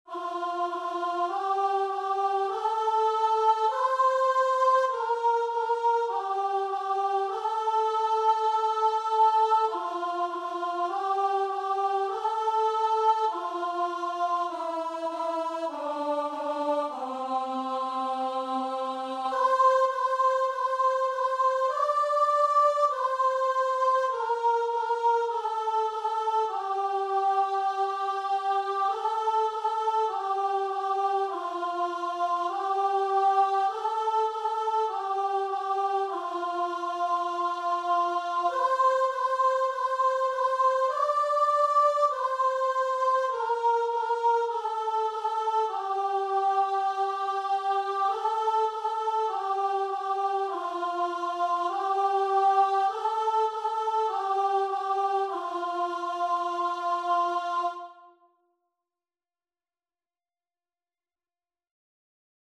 Christian
4/4 (View more 4/4 Music)
Guitar and Vocal  (View more Easy Guitar and Vocal Music)
Classical (View more Classical Guitar and Vocal Music)